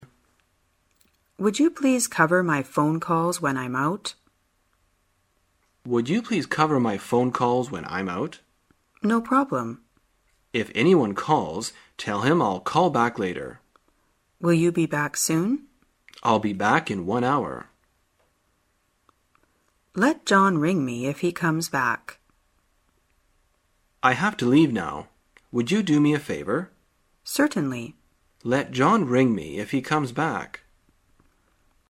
在线英语听力室生活口语天天说 第39期:怎样表达打电话的听力文件下载,《生活口语天天说》栏目将日常生活中最常用到的口语句型进行收集和重点讲解。真人发音配字幕帮助英语爱好者们练习听力并进行口语跟读。